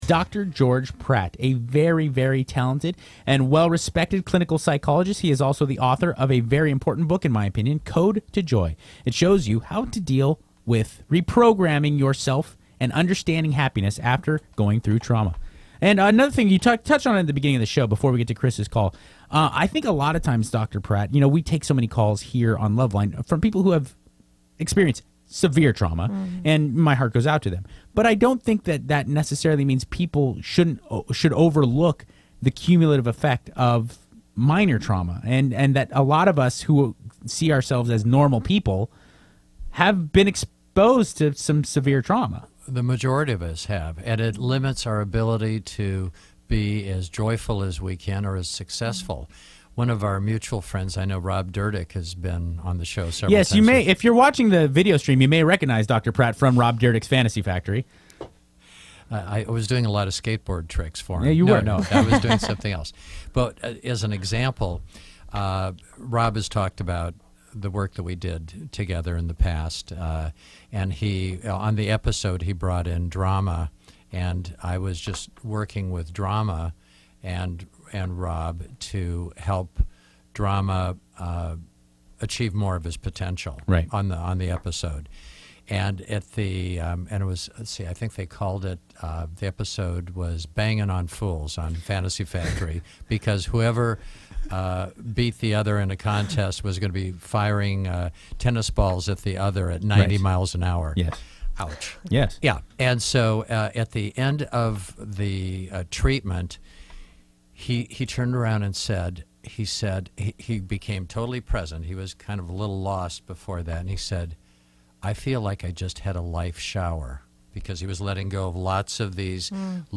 Loveline is a call-in question-and-answer radio show with the primary goal of helping youth and young adults with relationship, sexuality, and drug addiction problems.